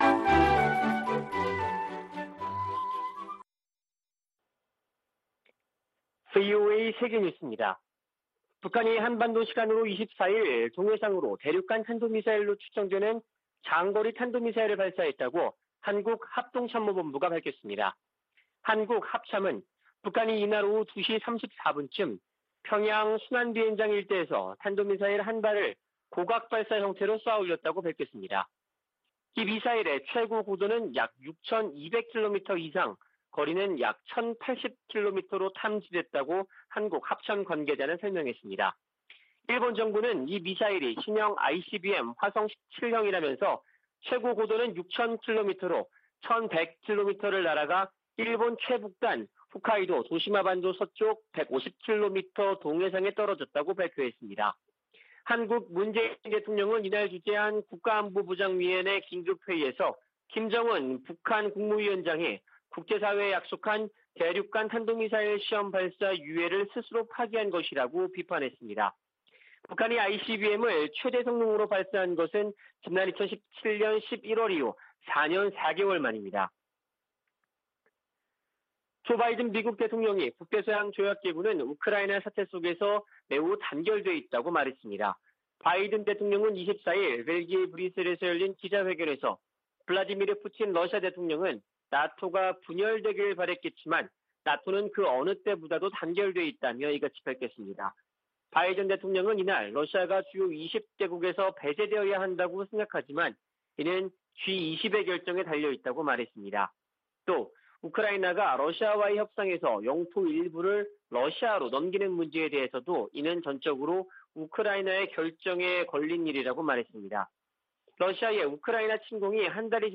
VOA 한국어 아침 뉴스 프로그램 '워싱턴 뉴스 광장' 2022년 3월 25일 방송입니다. 북한이 24일 대륙간탄도미사일(ICBM)으로 추정되는 미사일을 발사했습니다.